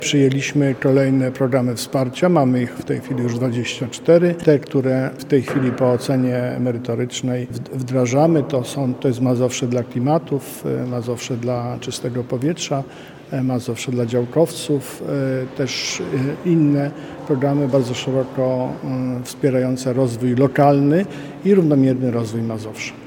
– To kolejny tego typu program – mówi marszałek Adam Struzik.